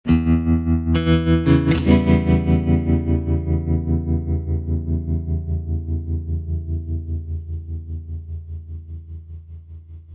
小型木吉他 " 学生吉他颤音G
描述：用小尺寸原声吉他上的钢锥敲击的颤音，用USB麦克风直接录制到笔记本电脑上。
标签： 吉他 规模小 颤音
声道立体声